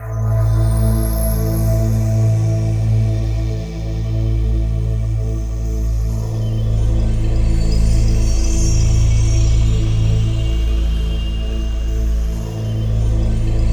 DM PAD1-14.wav